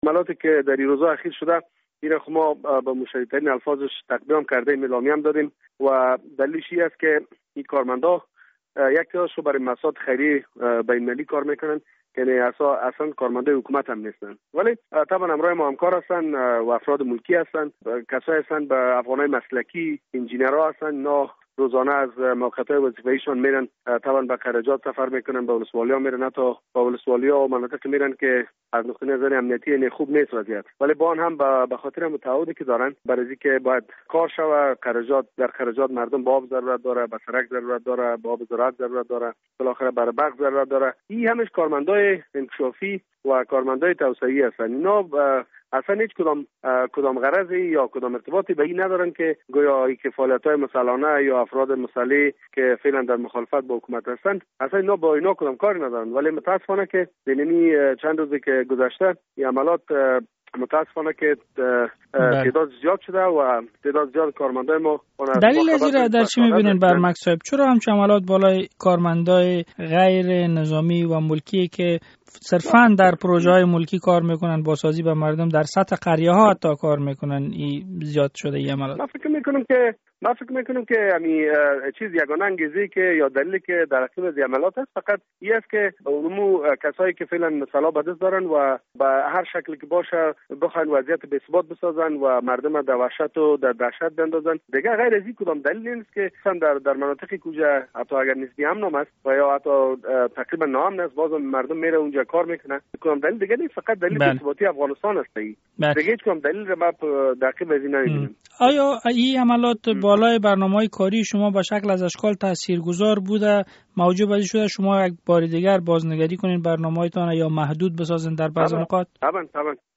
مصاحبه با ویس برمک وزیر احیا و انکشاف دهات افغانستان